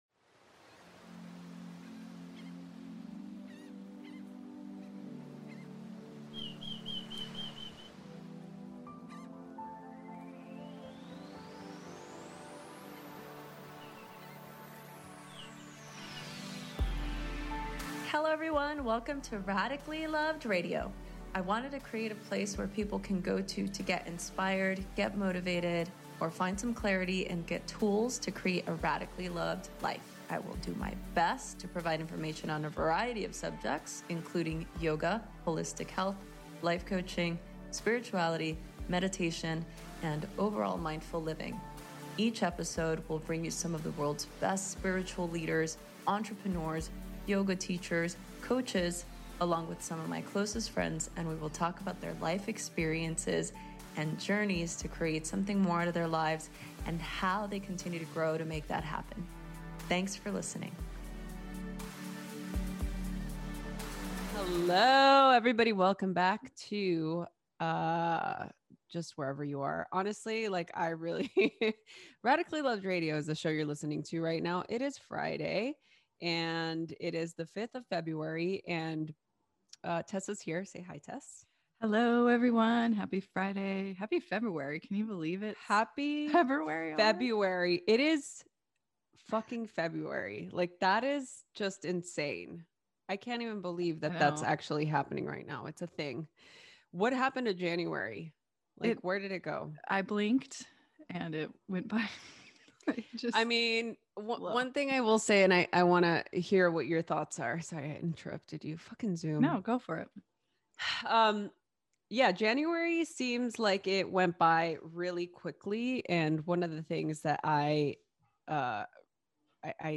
Jen Sincero joins me to show us how to stick to good habits, break free of our roadblocks, avoid our obstacles, and create boundaries that lead to success and radical love.